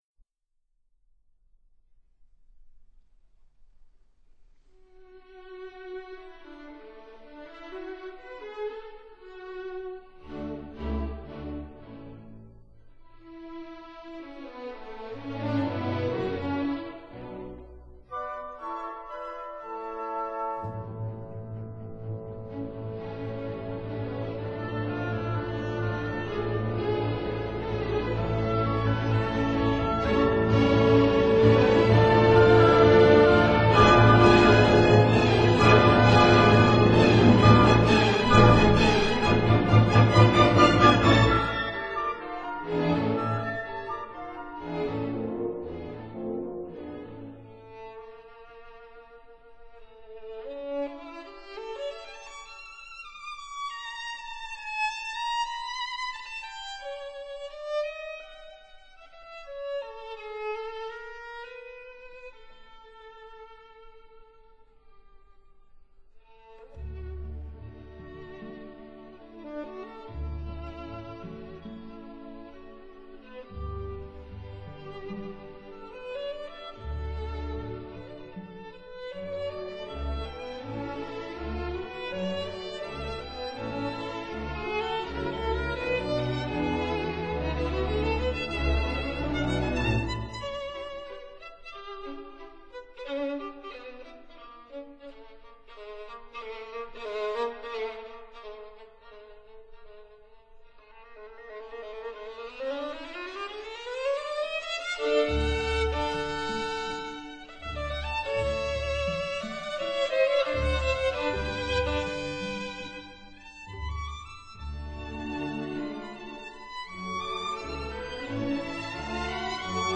这部小提琴协奏曲是一部欢快、活泼、充满青春气息的作品，它歌唱青春，歌唱生命，表现了俄罗斯人民的乐观主义精神。
全曲共分三个乐章： 第一乐章，中庸的快板，D大调，4/4拍子，奏鸣曲形式。
具有俄罗斯民间音乐的宽广气息和明朗悠扬的诗意。
主要旋律真挚动人，略带伤感。